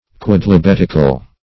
Meaning of quodlibetical. quodlibetical synonyms, pronunciation, spelling and more from Free Dictionary.